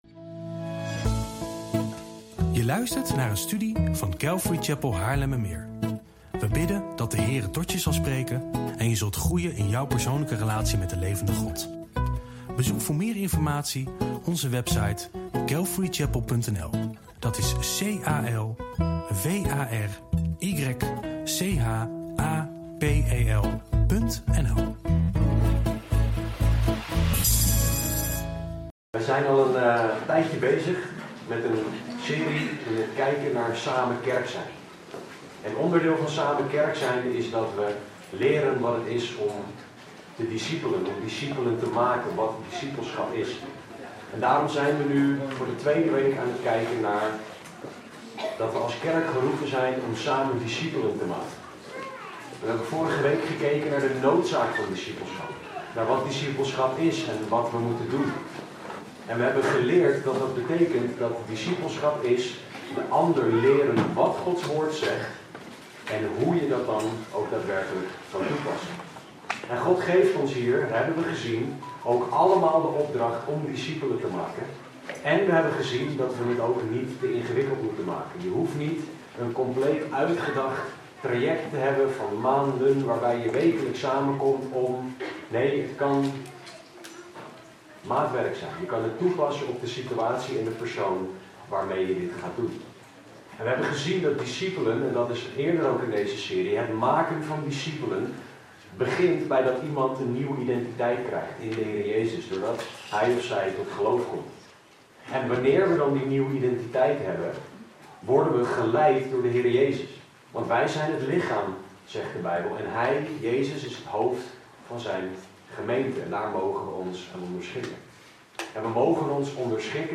PREKENSERIE